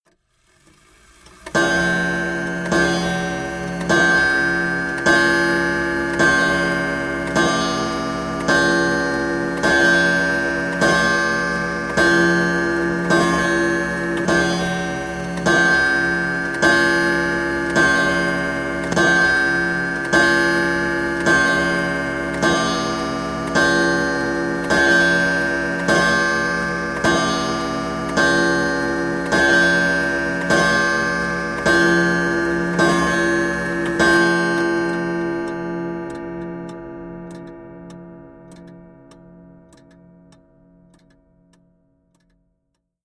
29 Chime
Tags: clock